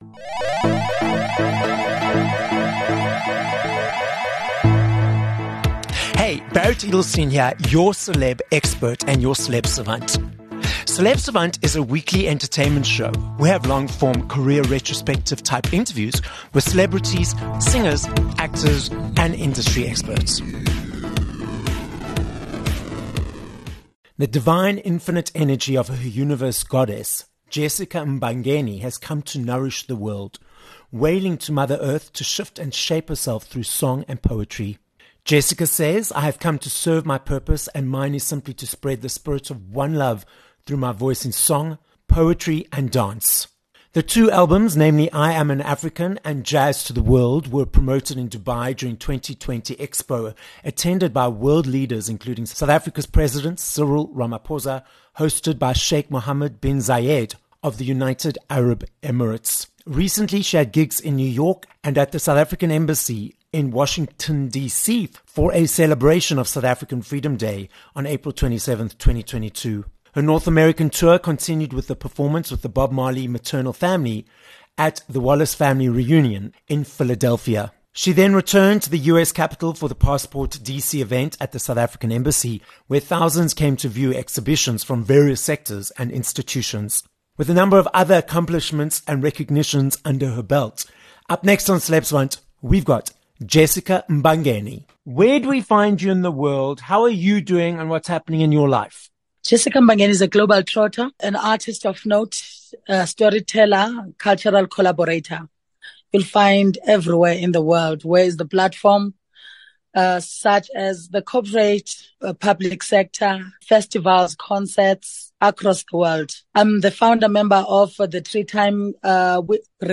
19 Sep Interview with Jessica Mbangeni